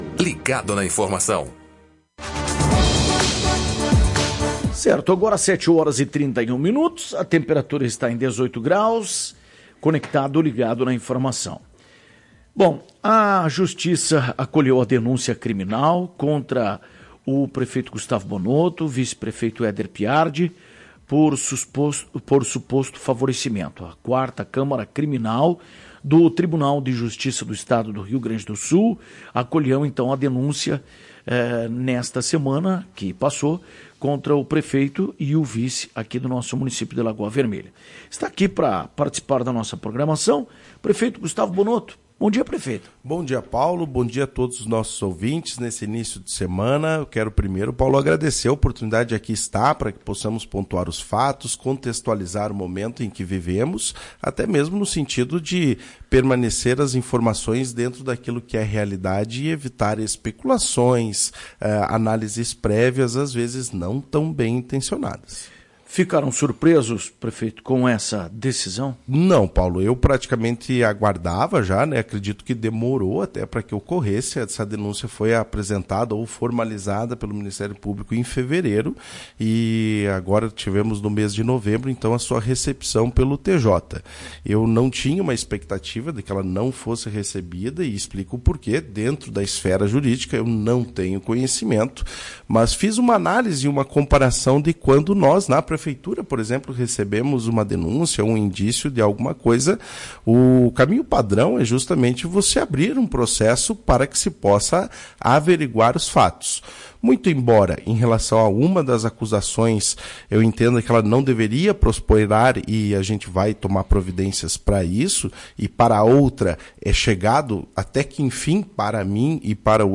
Confiante de ter agido corretamente, Bonotto afirmou em entrevista à Tua Rádio Cacique, que o processo de licitação, alvo de investigação do Ministério Público, gerou economia de R$ 50 mil por mês ao município.